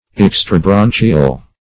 Search Result for " extrabranchial" : The Collaborative International Dictionary of English v.0.48: Extrabranchial \Ex`tra*bran"chi*al\, a. (Anat.)